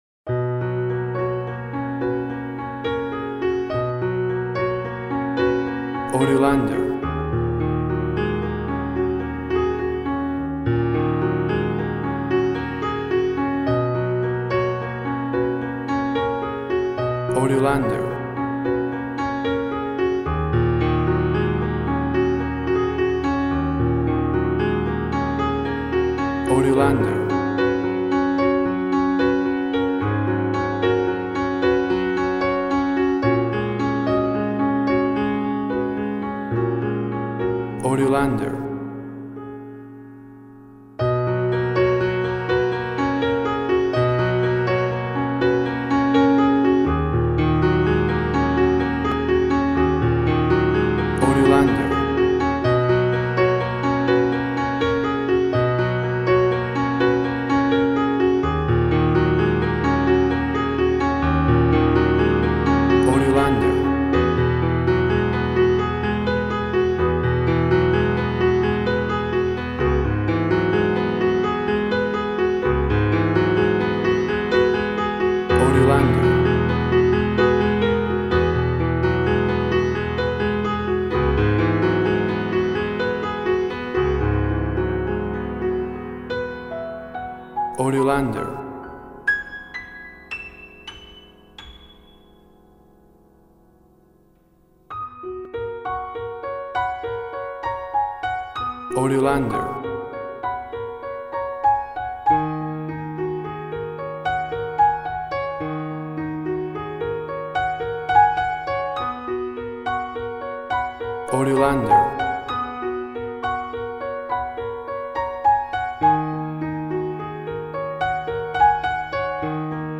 Soft piano music of calm and reflective character.
Tempo (BPM) 80